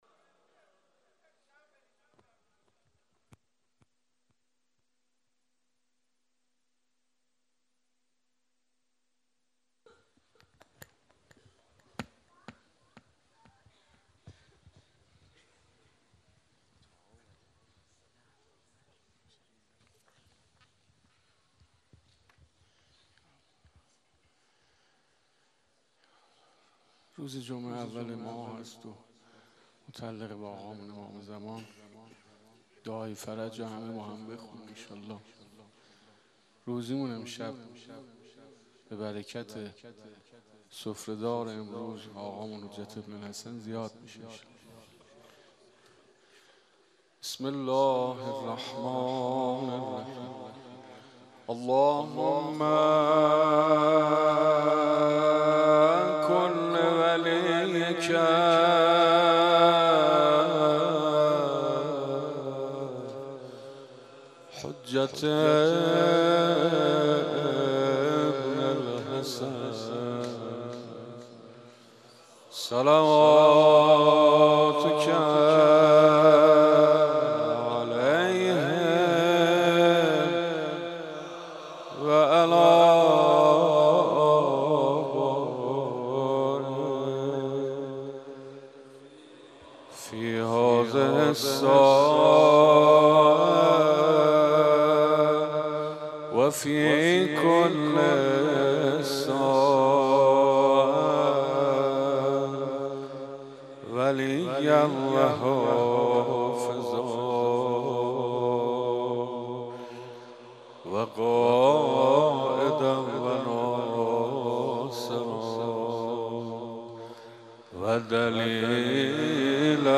خبرگزاری تسنیم: در «تربت عشق» قصد داریم در روزهای عزاداری محرم به ذکر فضایل و زندگی‌نامه یکی از شهدای گران‌قدر کربلا به‌همراه صوت نواهای مذهبی و آداب عزاداری در نقاط مختلف کشور و جهان بپردازیم.